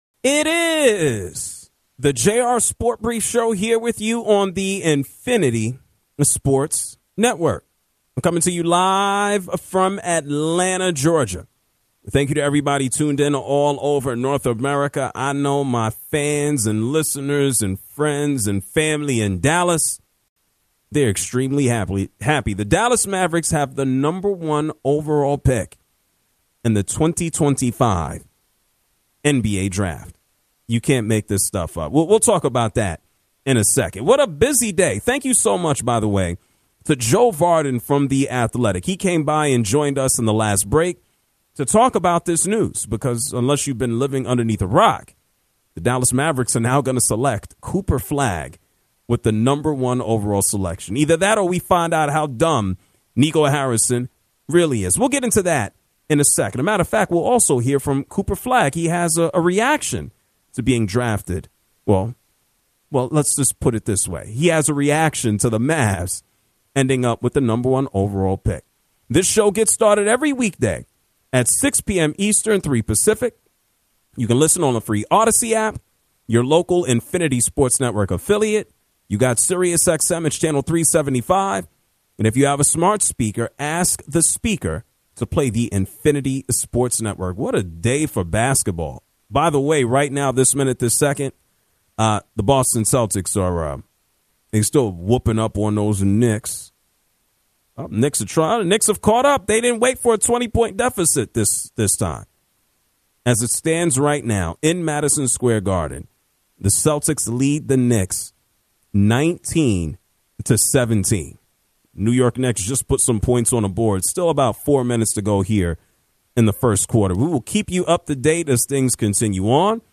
callers from Dallas and all over the country go back-and-forth